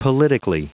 Prononciation du mot politically en anglais (fichier audio)
Prononciation du mot : politically